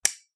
slide-in.mp3